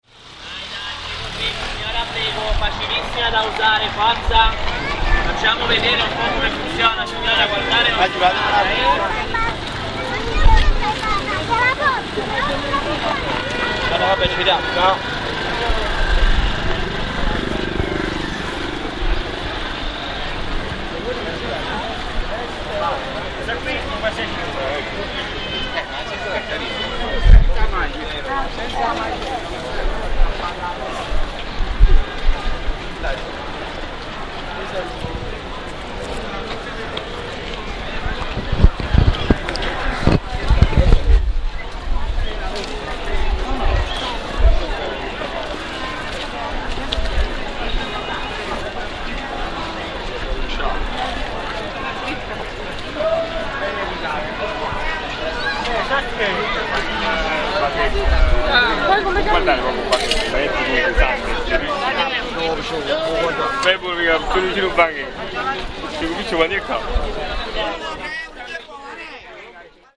Voices at market place Vomero
Sound recordings for research on street markets for Streets of Naples
Voices-at-market-place-Vomero1.mp3